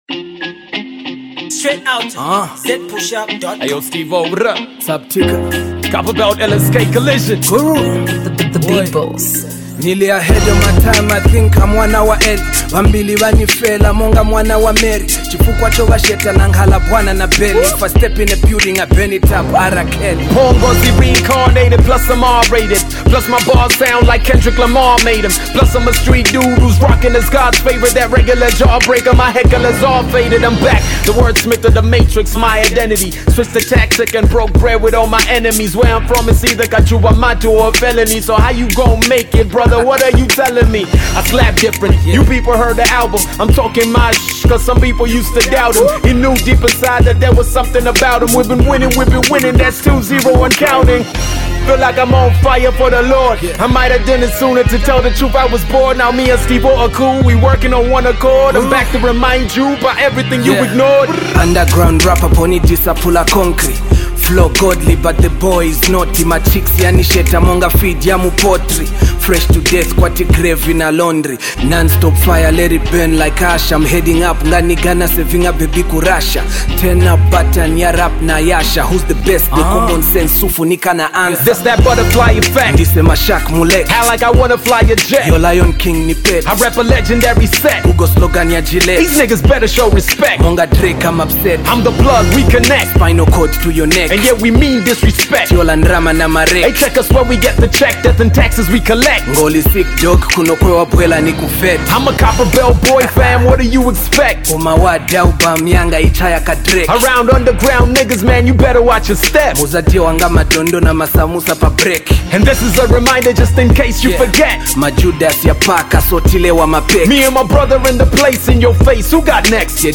Real HipHop jam